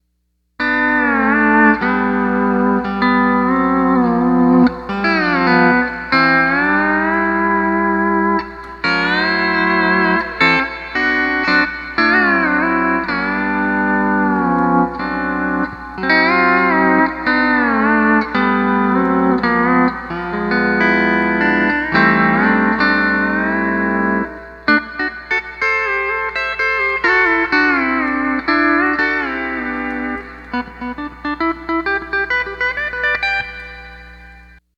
Steel Guitar Tab Page 16 / Lessons
Tab 606 - Sound Check - Neat E9th Open Chord - Setup Description Tab